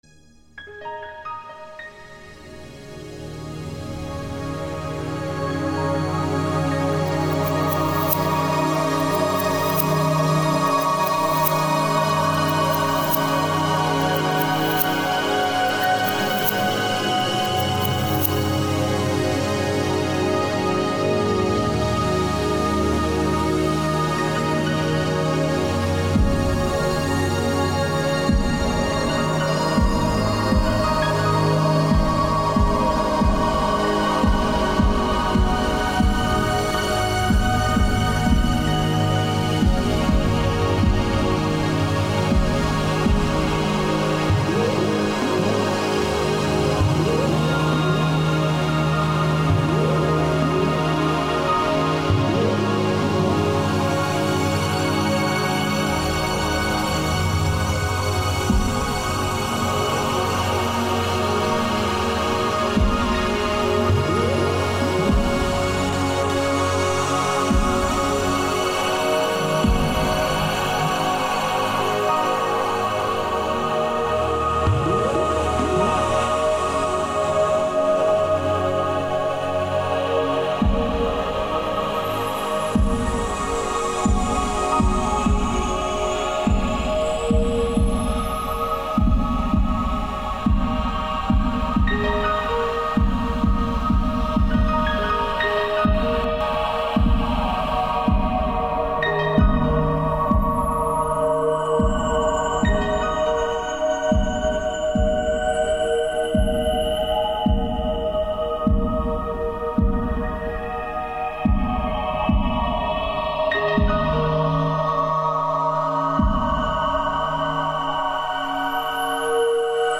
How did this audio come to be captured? These samples were recorded directly from eden while running.